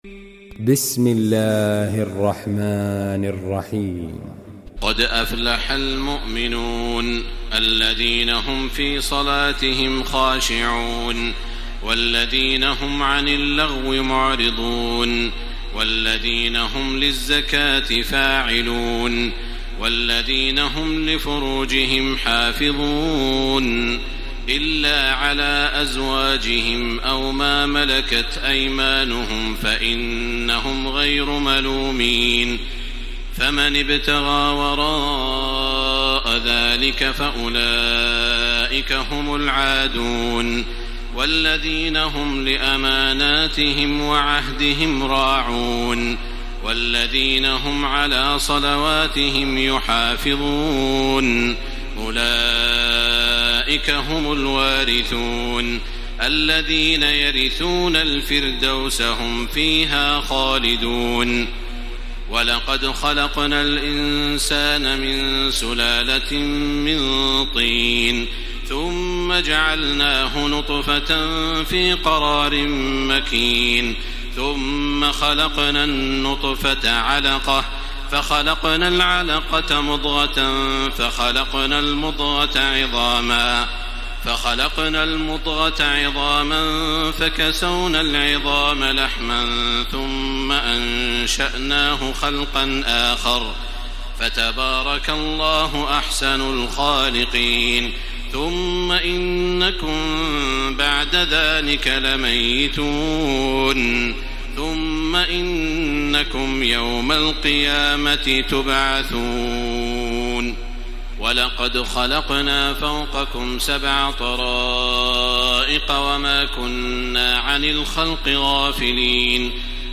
تراويح الليلة السابعة عشر رمضان 1434هـ سورتي المؤمنون و النور (1-20) Taraweeh 17 st night Ramadan 1434H from Surah Al-Muminoon and An-Noor > تراويح الحرم المكي عام 1434 🕋 > التراويح - تلاوات الحرمين